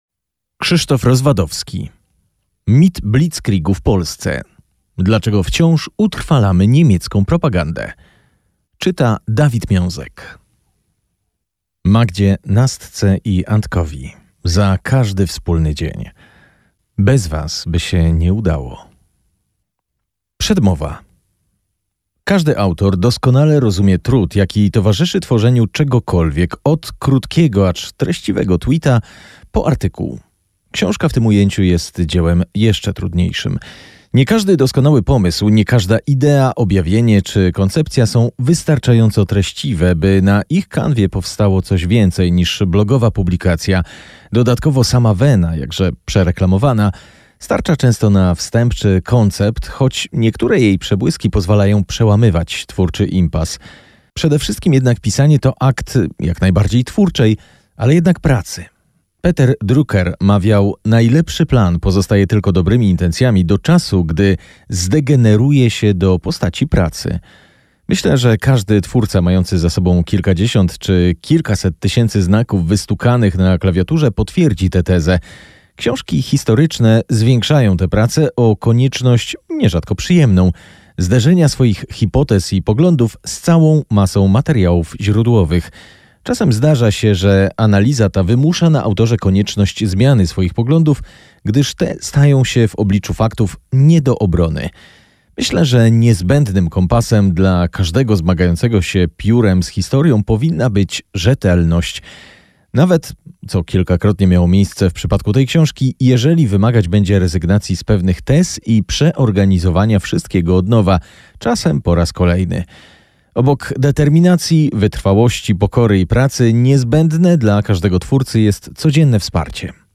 Posłuchaj fragmentu książki: MP3